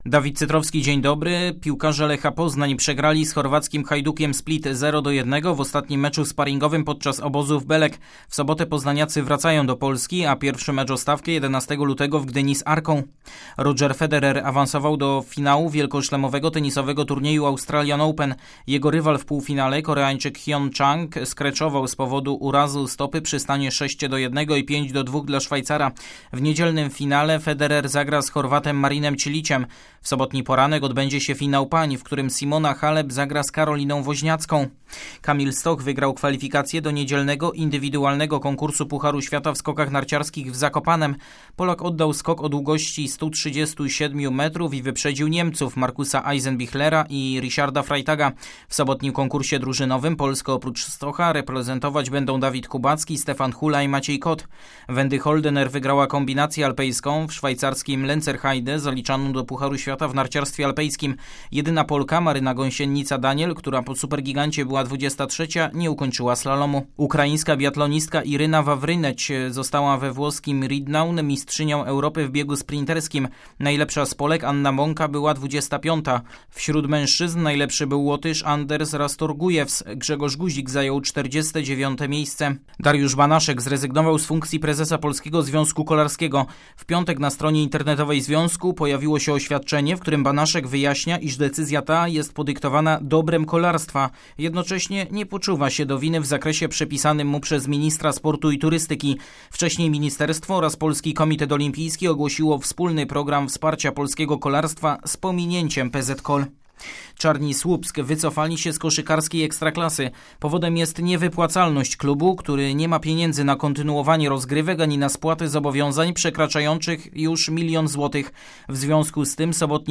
27.01 serwis sportowy godz. 7:05